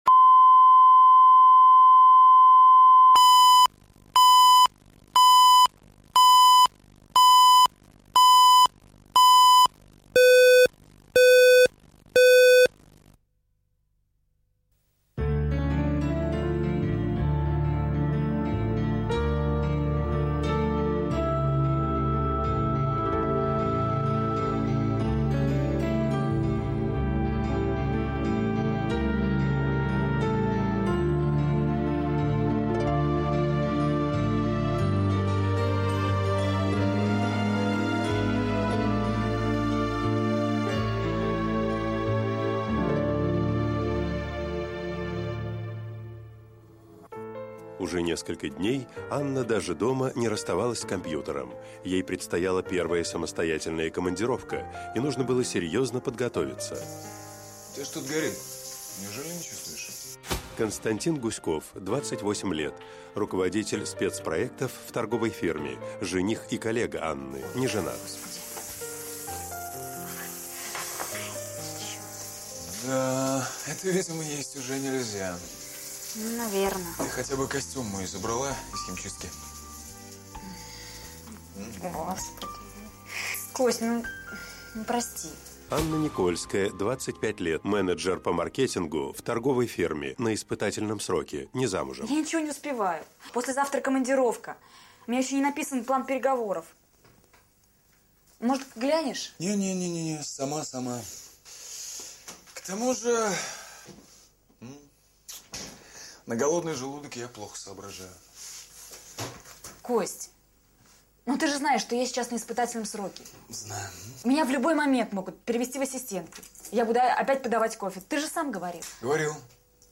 Аудиокнига Женские секреты | Библиотека аудиокниг